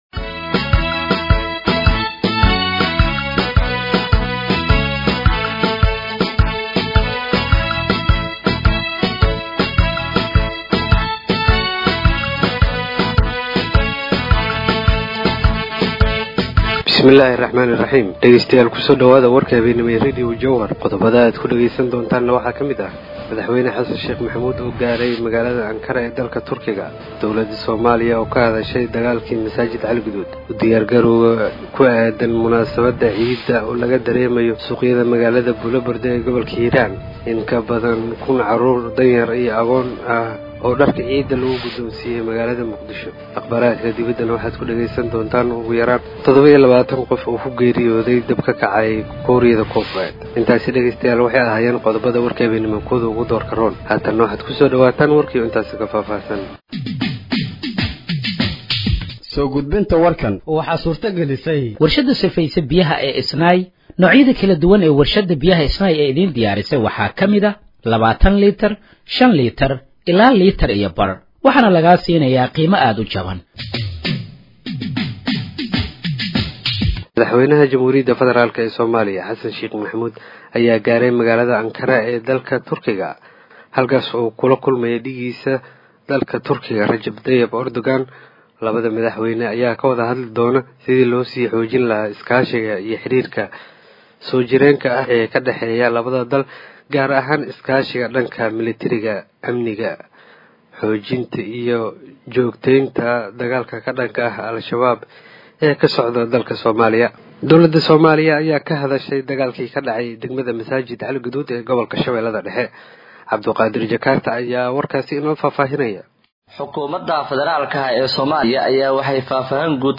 Dhageeyso Warka Habeenimo ee Radiojowhar 27/03/2025
Halkaan Hoose ka Dhageeyso Warka Habeenimo ee Radiojowhar